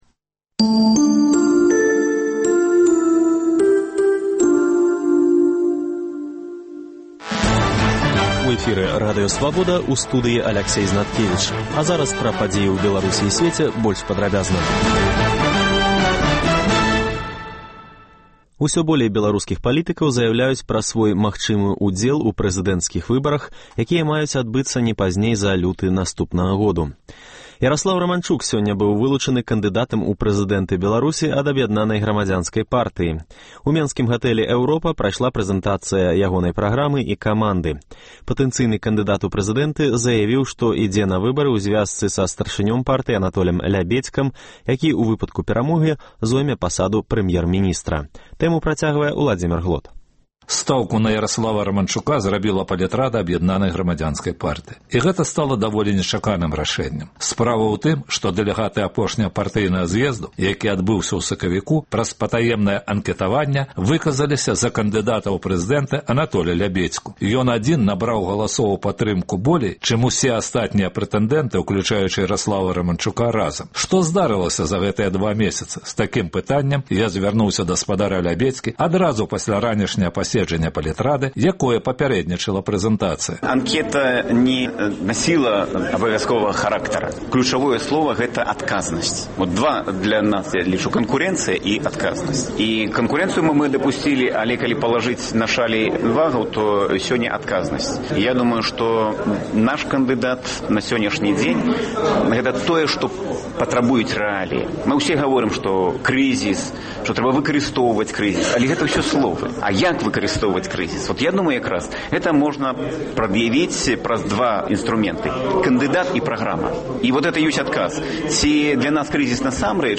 Паведамленьні нашых карэспандэнтаў, званкі слухачоў, апытаньні ў гарадах і мястэчках Беларусі